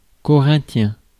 Ääntäminen
Ääntäminen France: IPA: [kɔ.ʁɛ̃.tjɛ̃] Haettu sana löytyi näillä lähdekielillä: ranska Käännös Ääninäyte Adjektiivit 1.